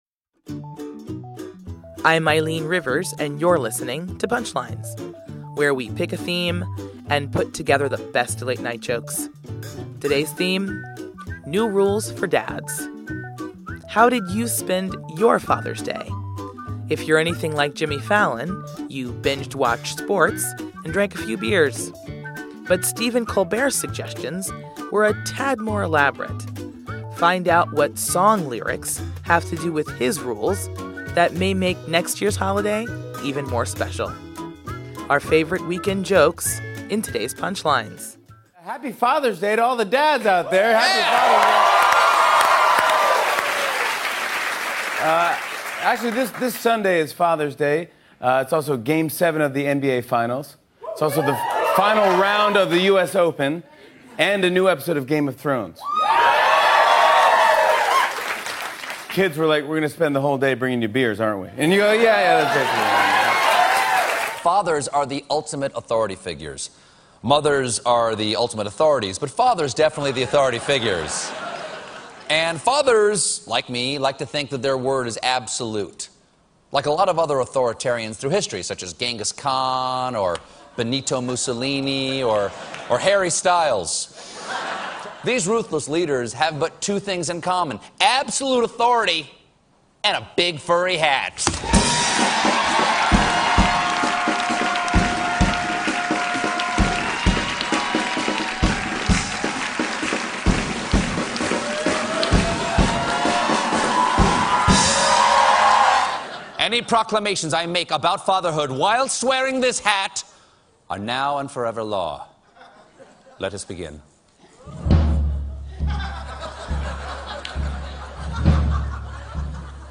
The late-night comics take a look at the perfect Father's Day.